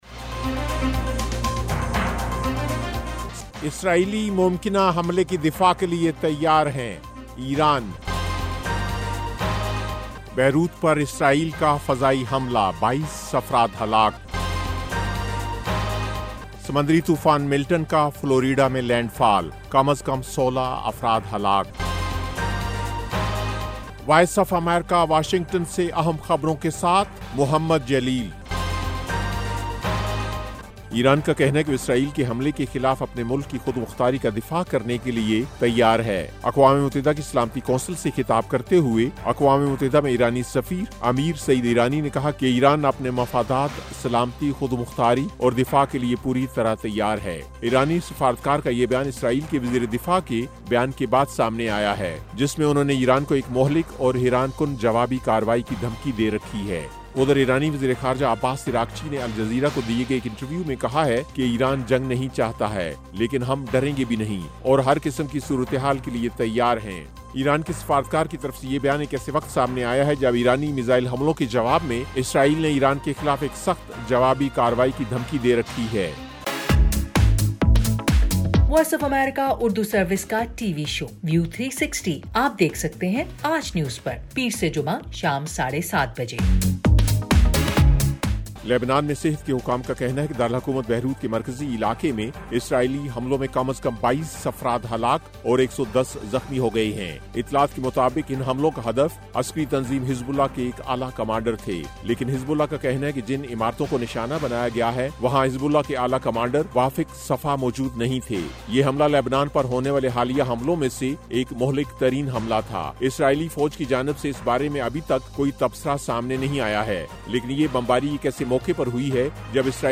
ایف ایم ریڈیو نیوز بلیٹن: شام 6 بجے